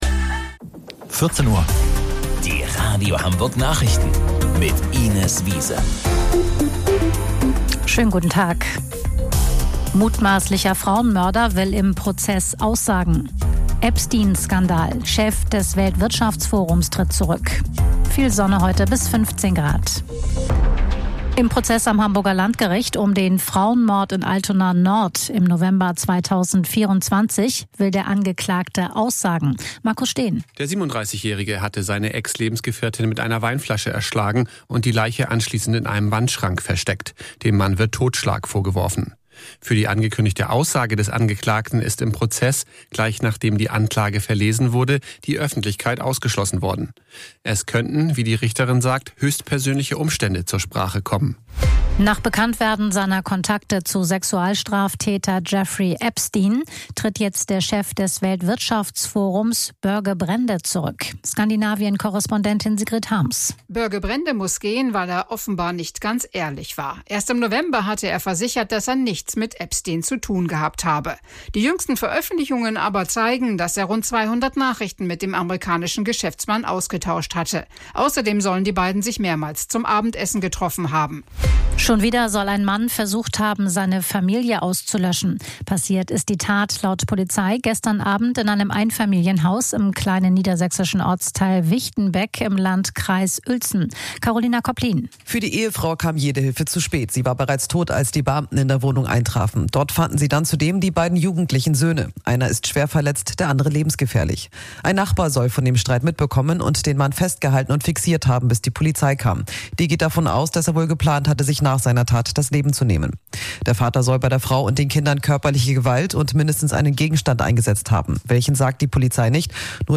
Radio Hamburg Nachrichten vom 26.02.2026 um 14 Uhr